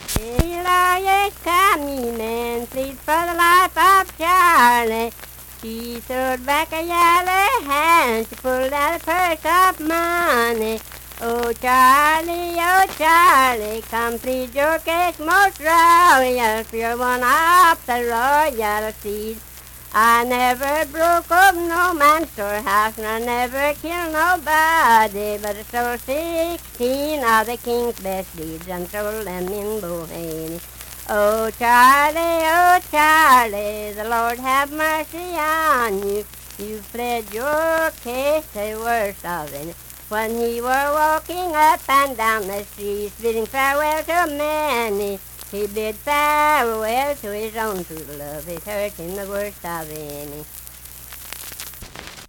Unaccompanied vocal music
Verse-refrain 3 (7w/R).
Voice (sung)